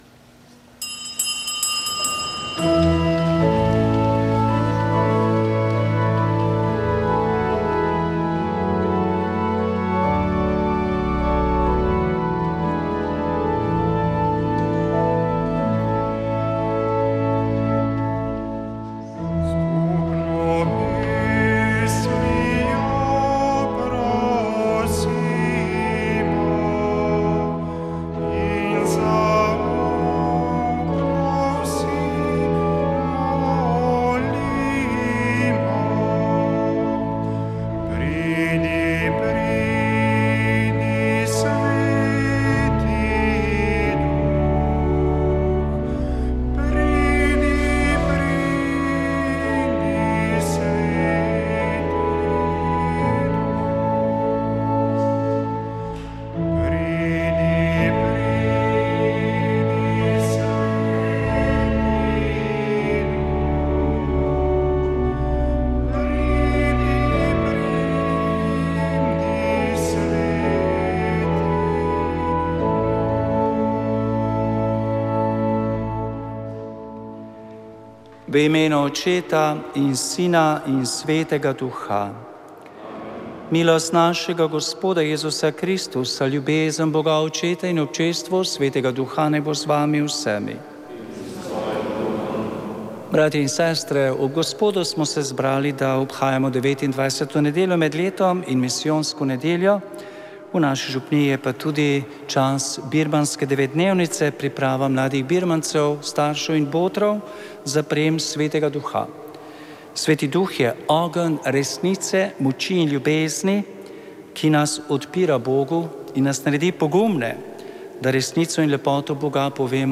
Sv. maša iz župnijske cerkve sv. Jožefa in sv. Barbare iz Idrije 28. 11.
pel domači pevski zbor.